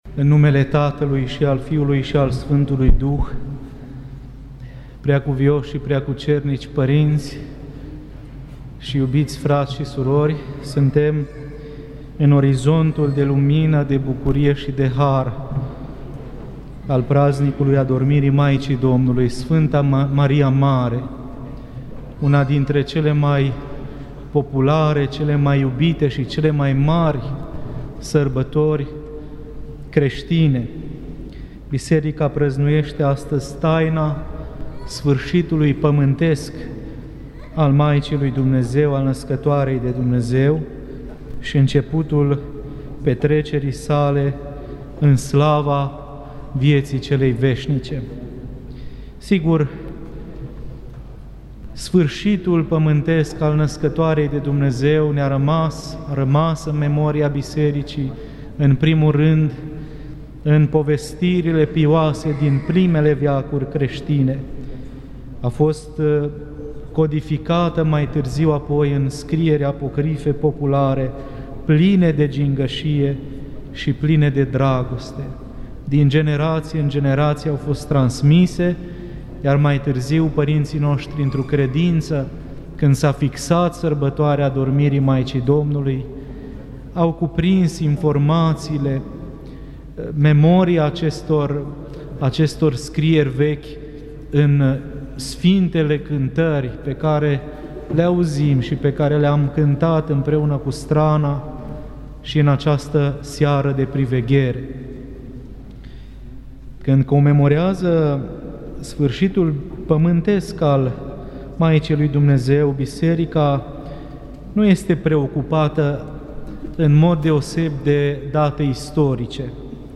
Predică la Slujba Prohodului Maicii Domnului